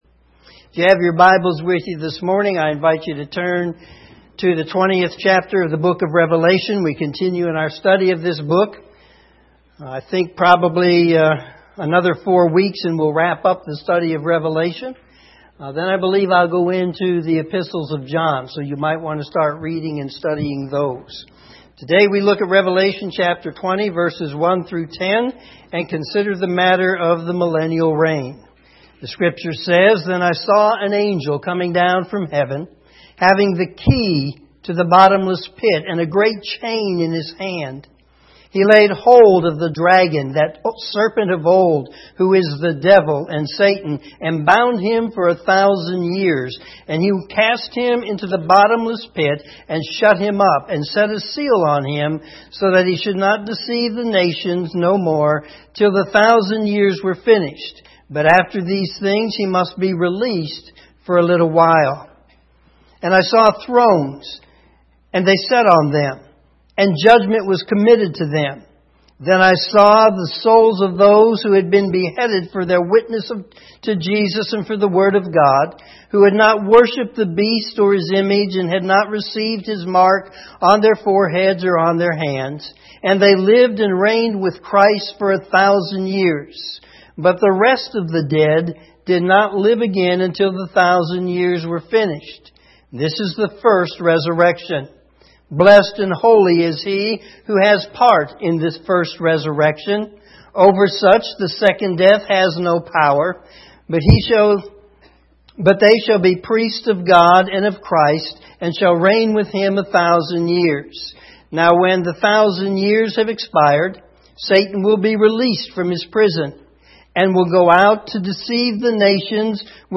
Morning Sermon Revelation 20:1-10